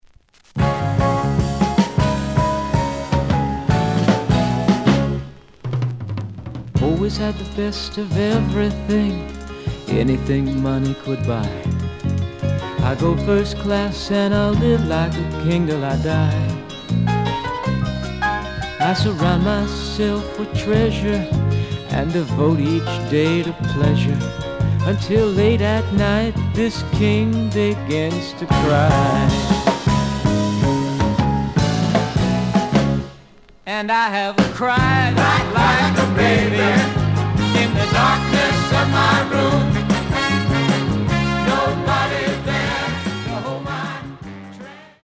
The audio sample is recorded from the actual item.
●Genre: Rock / Pop
Slight noise on beginning of A side, but almost good.